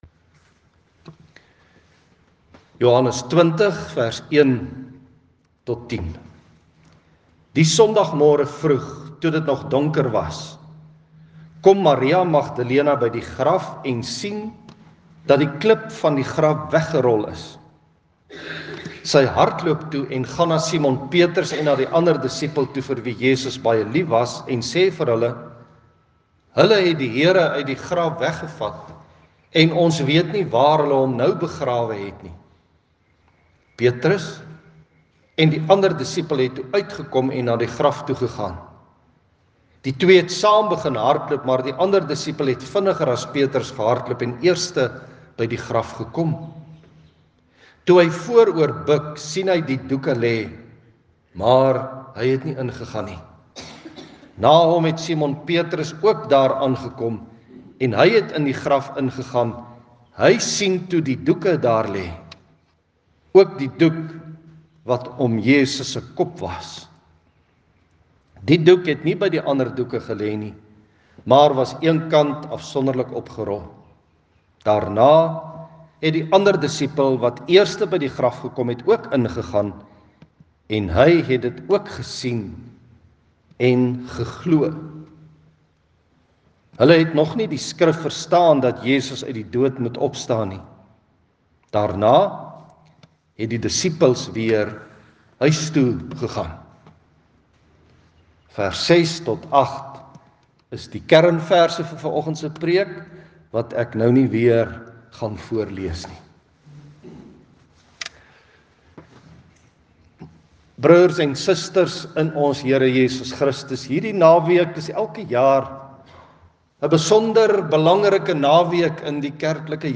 Vandag se preek gaan oor die almagtige wonder van ons Verlosser se opstanding uit die dood.